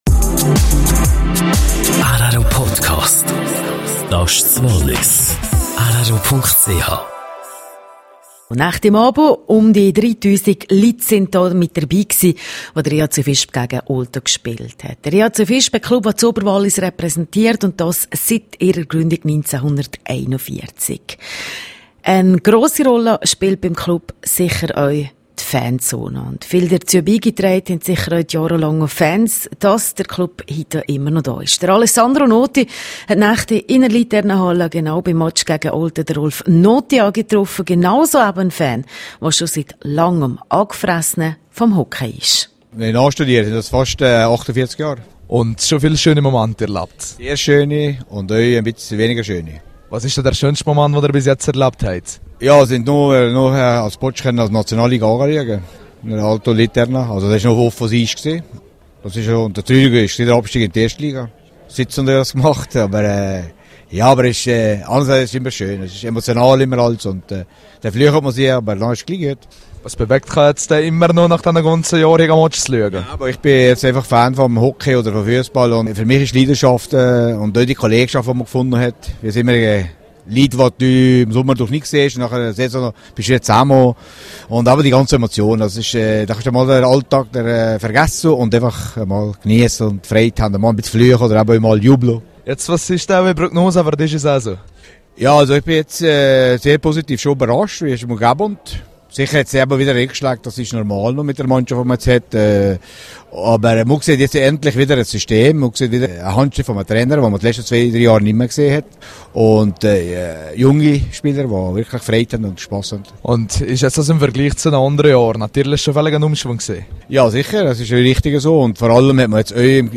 Wie jeder von uns den Abfallberg verkleinern kann. Interview